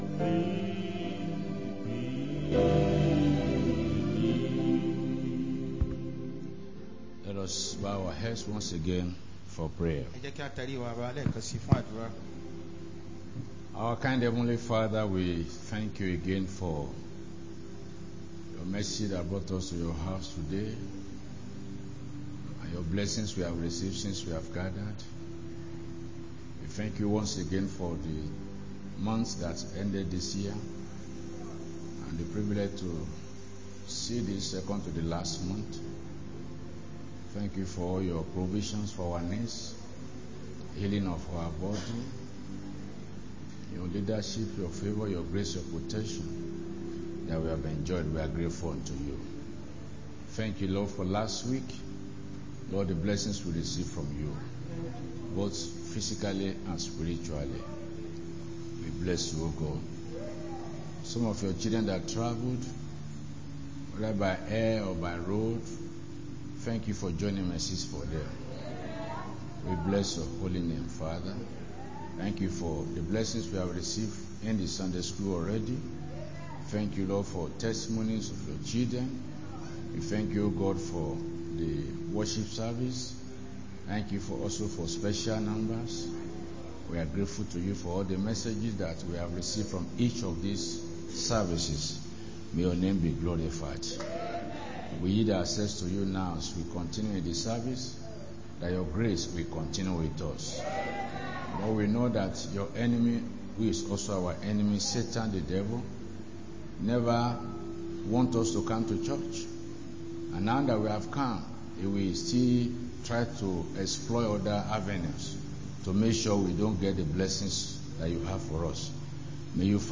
Sunday Main Service